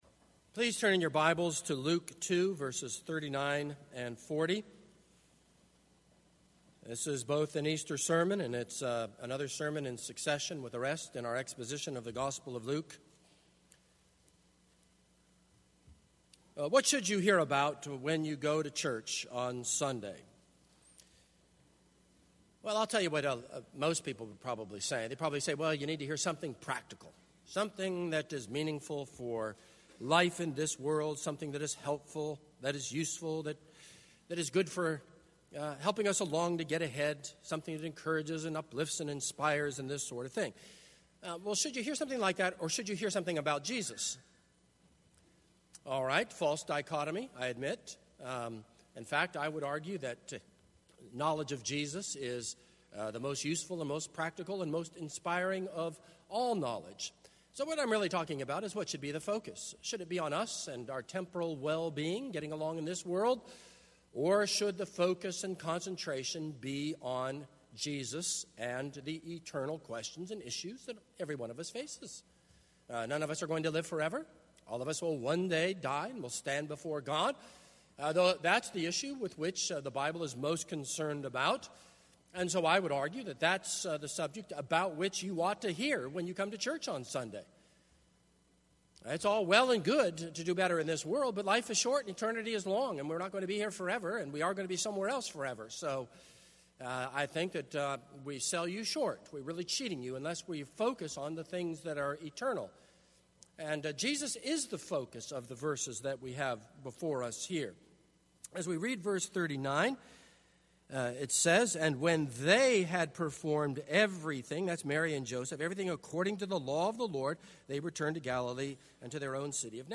This is a sermon on Luke 2:39-40.